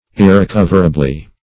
irrecoverably - definition of irrecoverably - synonyms, pronunciation, spelling from Free Dictionary
Ir`re*cov"er*a*bly, adv.